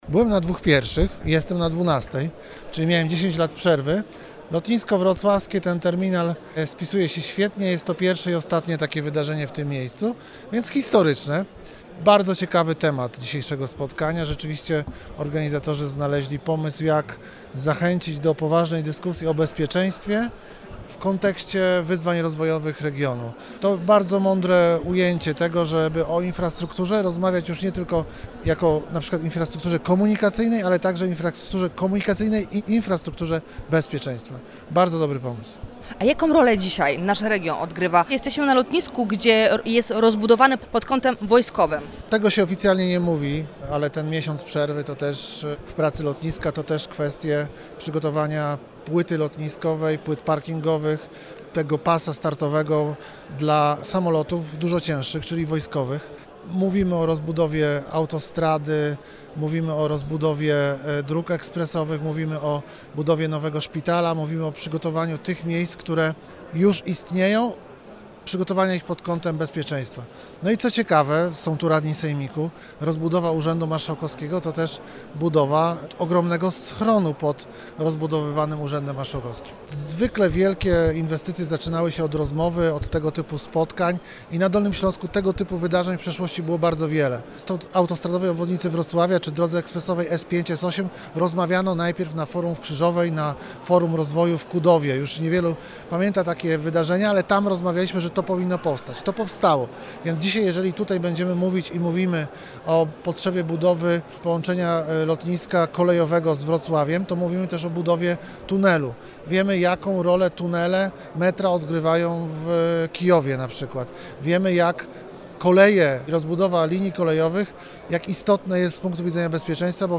Markiem Łapińskim – wiceprzewodniczącym Sejmiku i wiceprzewodniczącym klubu KO: